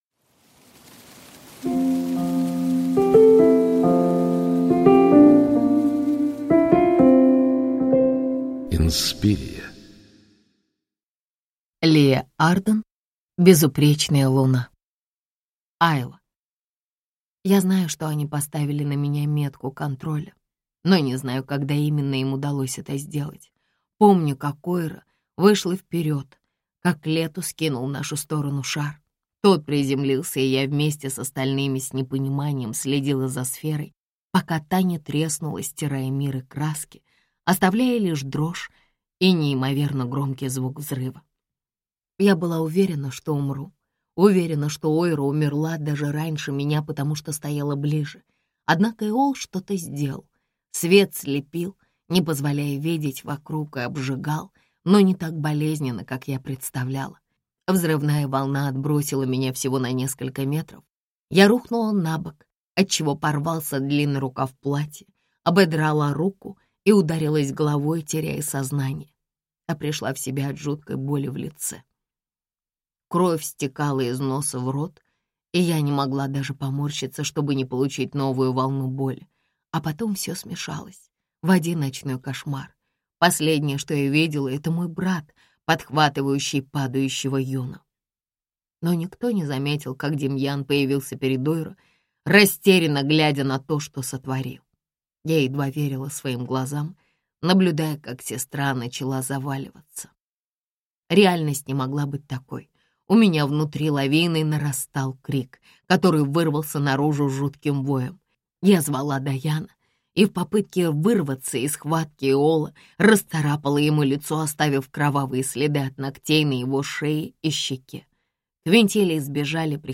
Аудиокнига Безупречная Луна | Библиотека аудиокниг